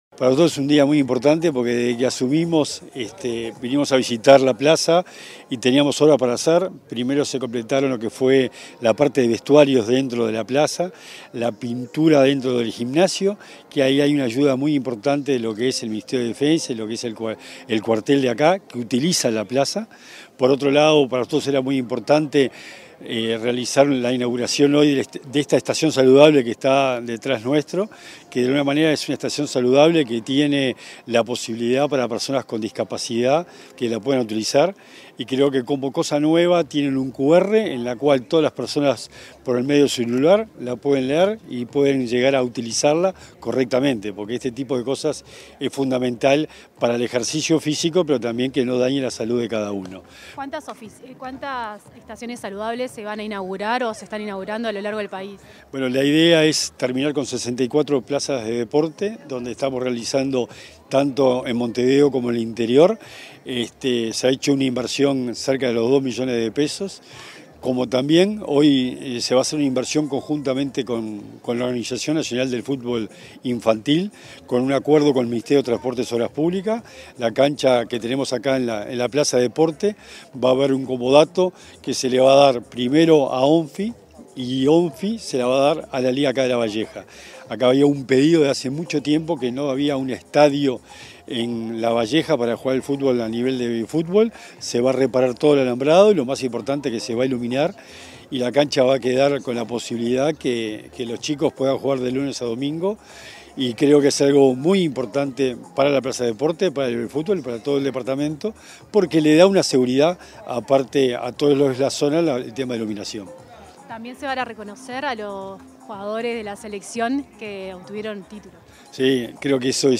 Entrevista al secretario nacional del Deporte, Sebastián Bauzá